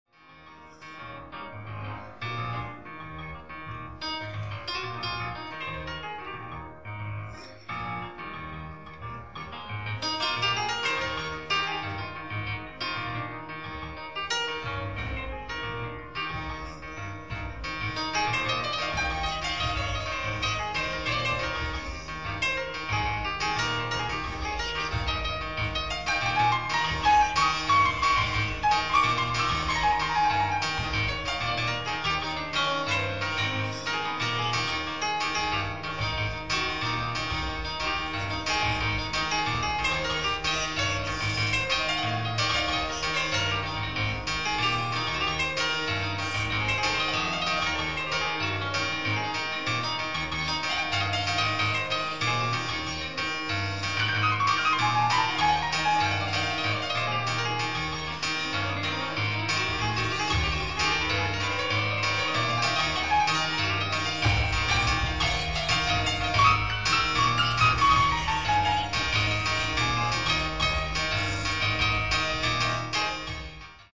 ライブ・アット・ノジャン・シュール・マルヌ、フランス
※試聴用に実際より音質を落としています。